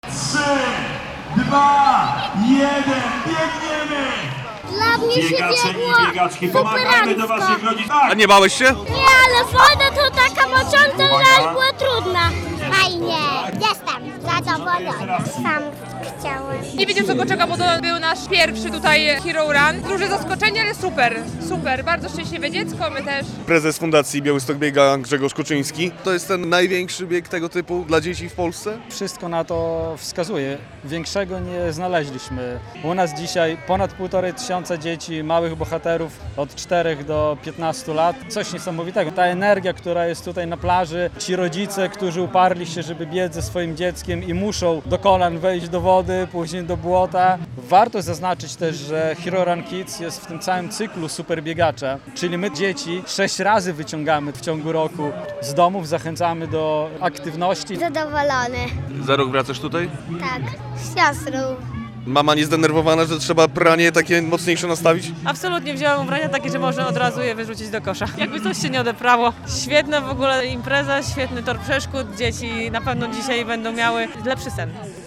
Hero Run Kids - relacja